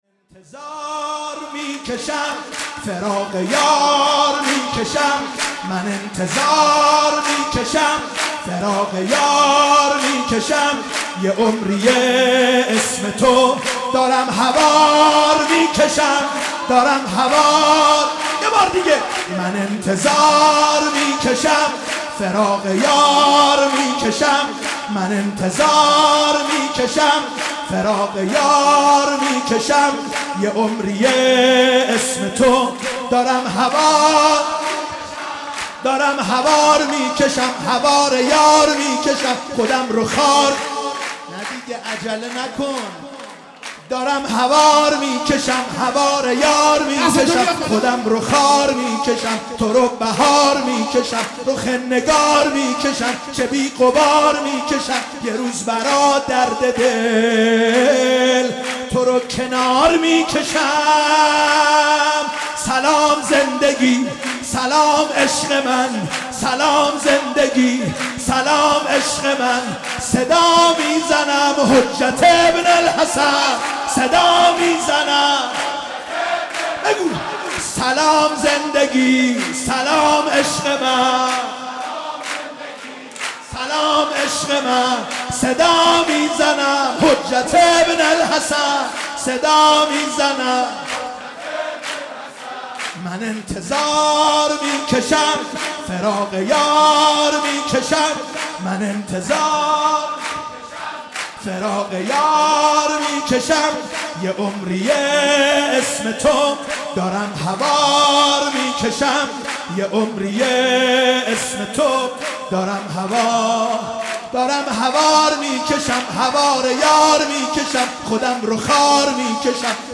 ولادت امام زمان (عج)